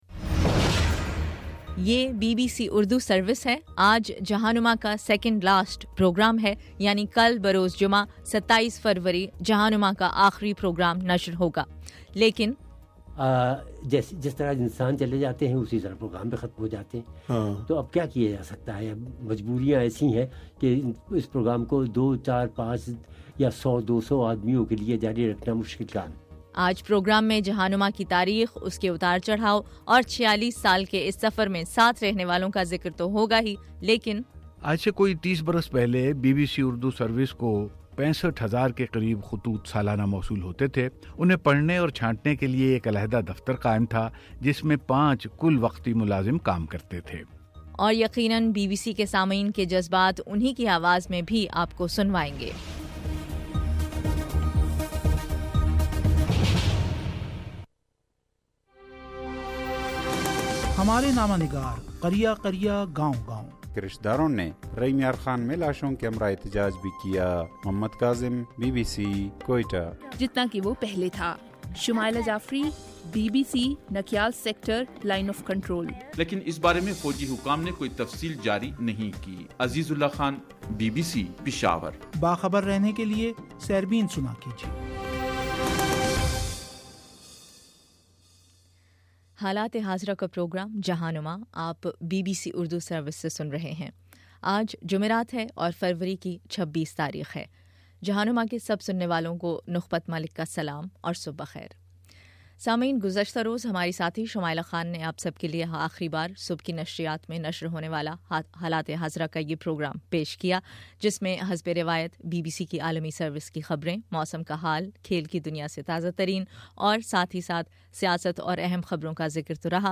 بی بی سی اردو نے 1969 میں جہاں نما پروگرام سامعین کے لیے پیش کرنا شروع کیا جس کا کل بروز جمعہ آخری پروگرام ہو گا۔ آج کے پروگرام میں ہم سامعین سے ان کی یادوں کے حوالے سے سنیں گے اور یہ بھی کہ پروگرام کیسے شروع ہوا۔